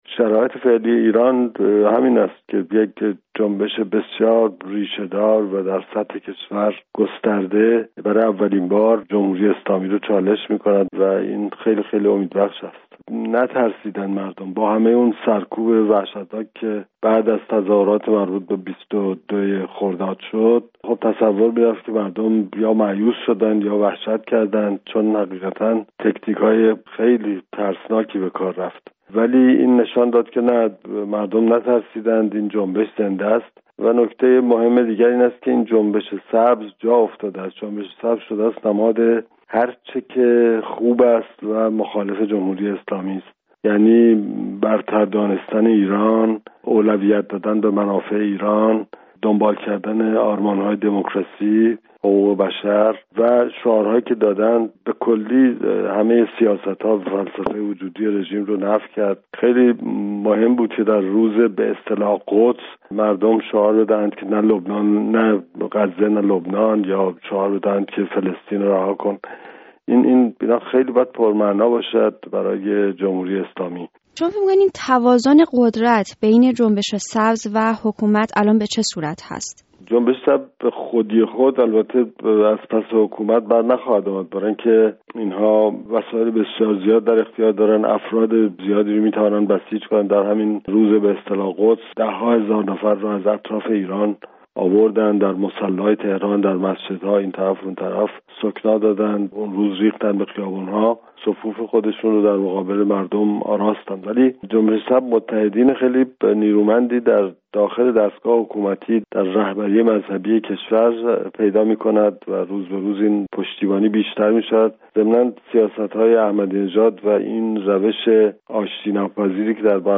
گفت‌وگو با داریوش همایون، مؤسس حزب مشروطه ایران و تحلیلگر سیاسی